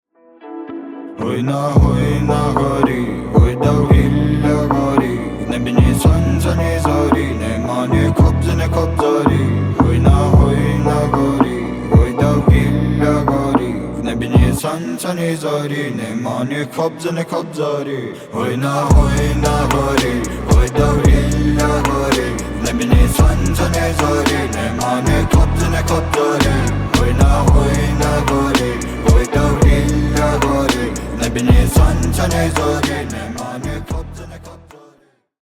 • Качество: 320 kbps, Stereo
грустные